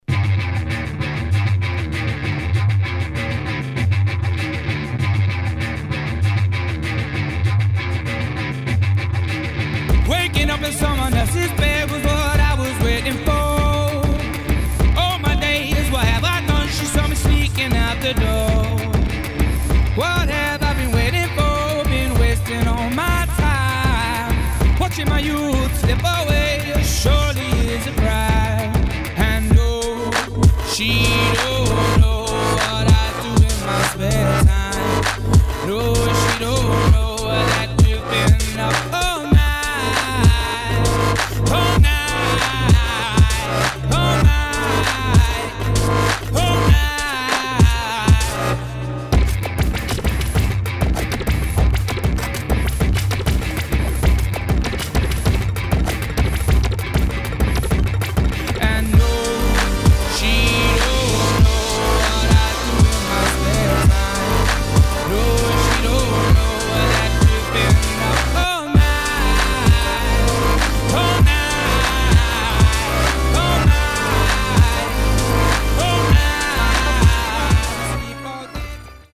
[ BASS / TRAP / DOWNBEAT ]